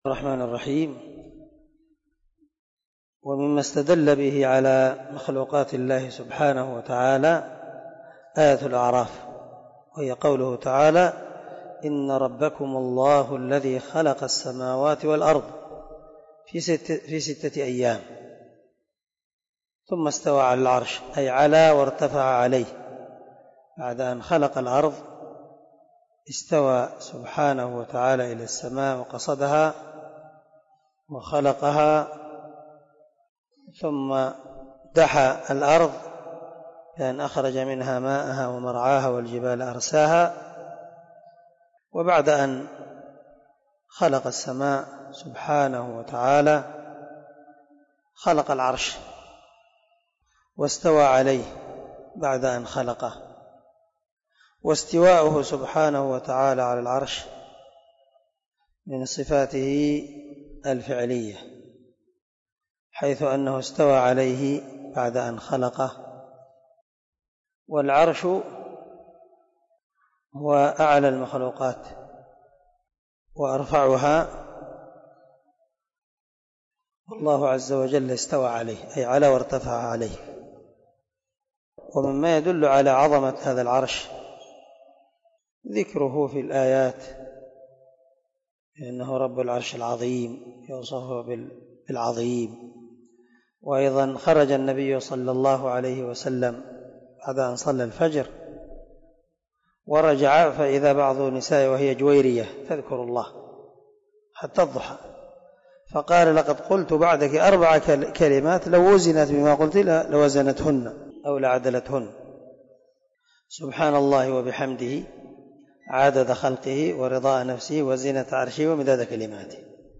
🔊 الدرس 10 من شرح الأصول الثلاثة